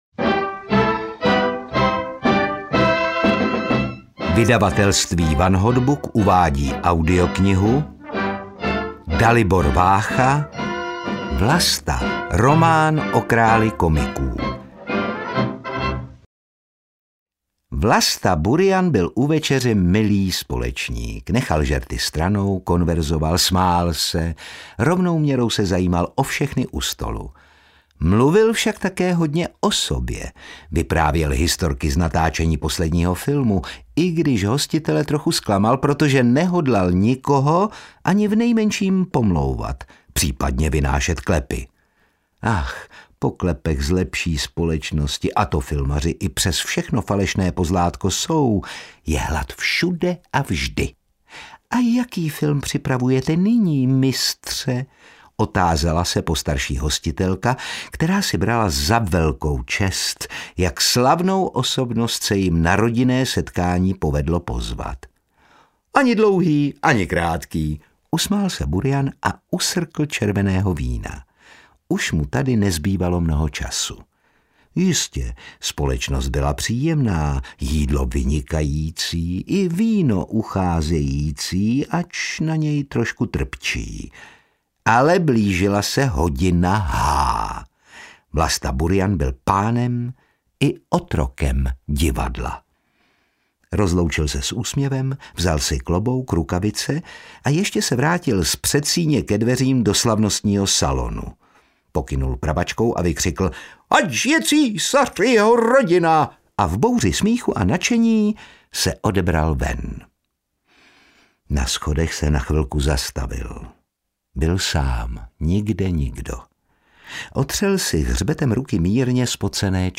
Vlasta – román o králi komiků audiokniha
Ukázka z knihy
• InterpretOtakar Brousek ml.